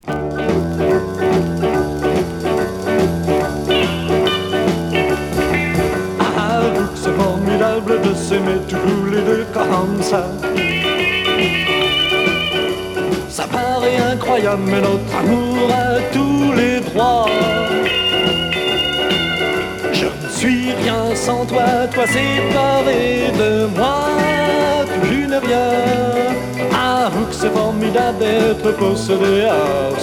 Country R'n'r